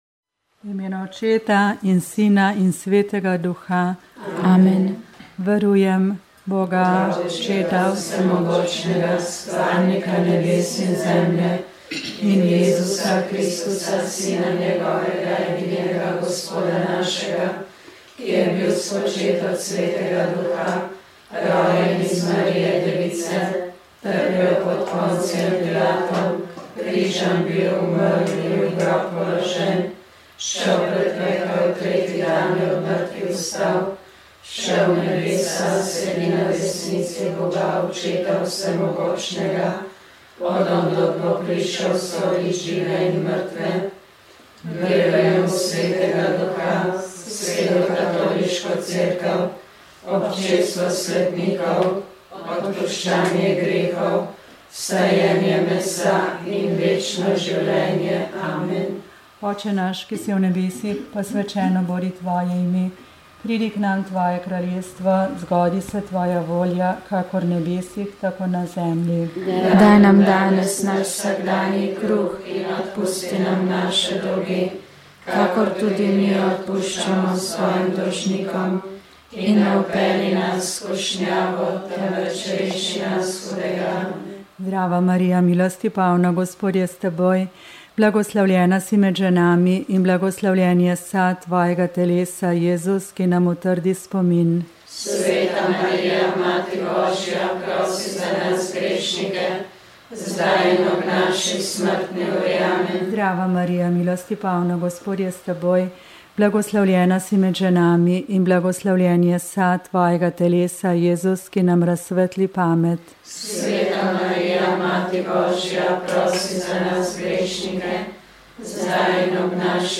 Rožni venec
Molile so redovnice - Marijine sestre.